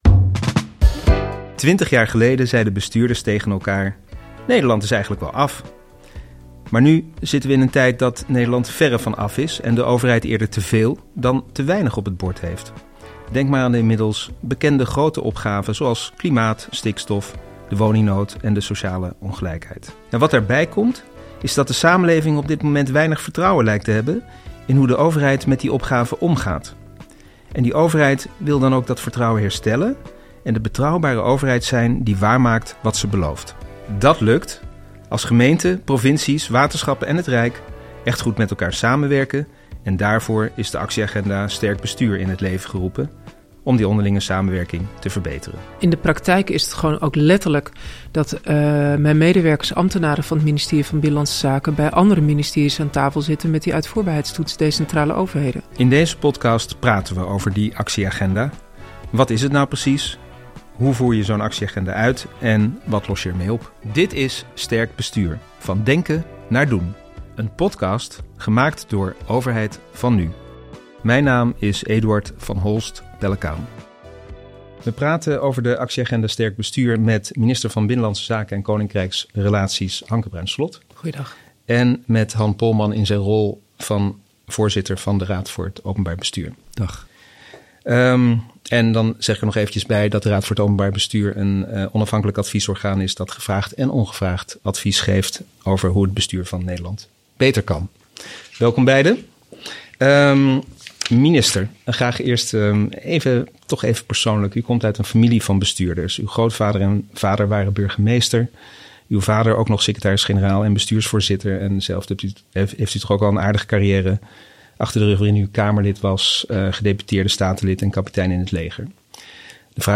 In de eerste aflevering van de podcast ‘Actieagenda Sterk Bestuur - van denken naar doen’ praten BZK-minister Hanke Bruins Slot en ROB-voorzitter Han Polman over de Actieagenda Sterk Bestuur en waartoe die moet leiden.